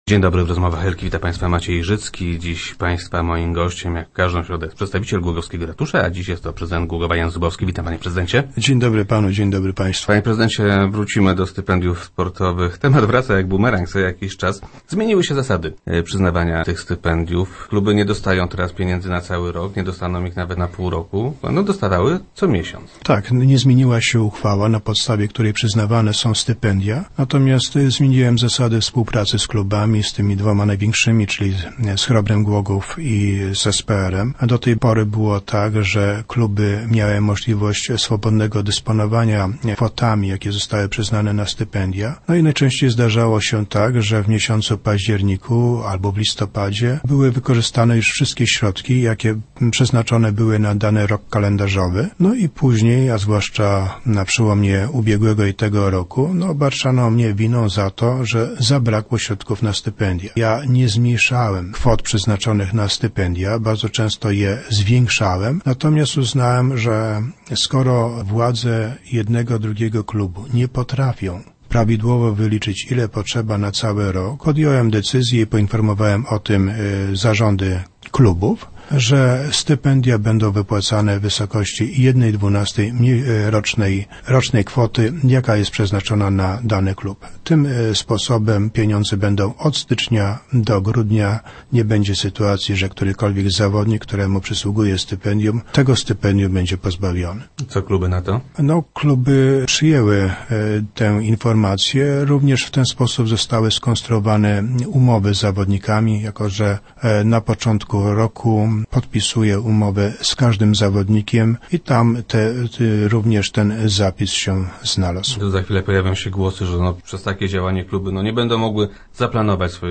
Tak było na przełomie ubiegłego i bieżącego roku - mówił prezydent Jan Zubowski, który był gościem Rozmów Elki.